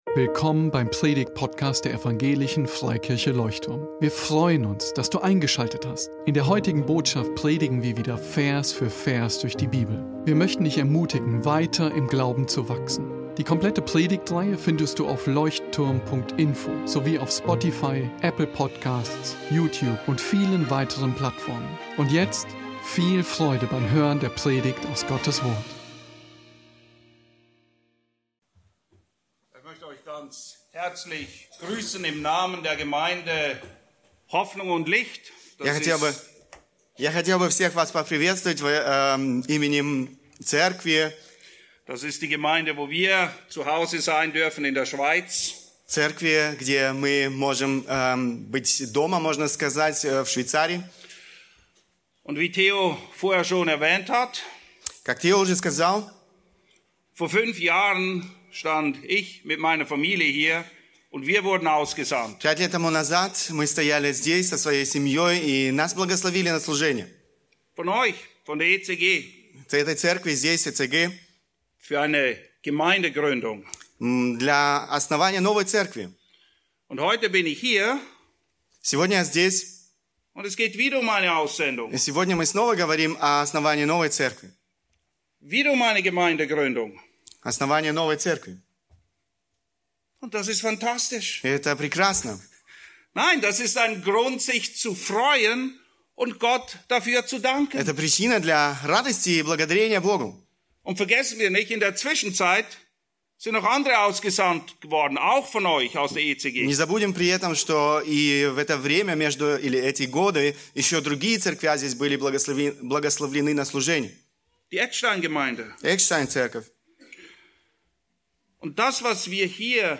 Predigt zur Aussendung der Gemeindegründung am 06. Oktober 2019 Der Beitrag Freude über Freude durch Gemeindegründung erschien zuerst auf Evangelische Freikirche Leuchtturm e.V..